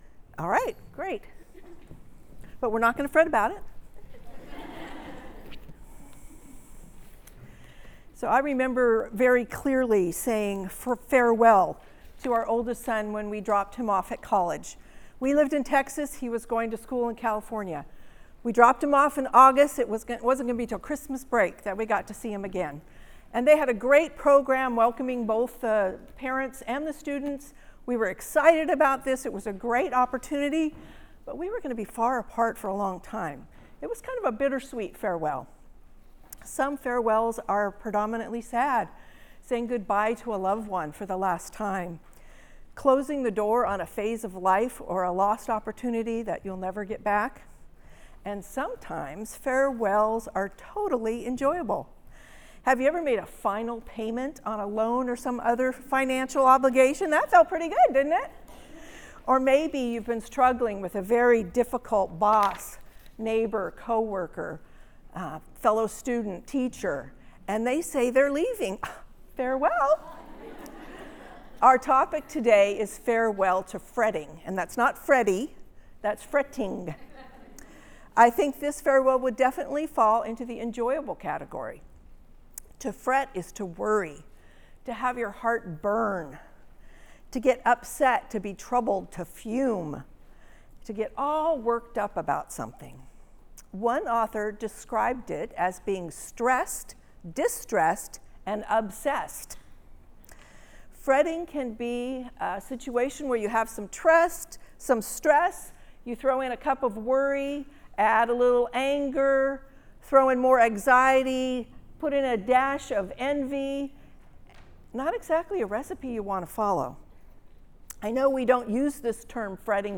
Women's Breakfast (Sermon) - Compass Bible Church Long Beach
Women's Breakfast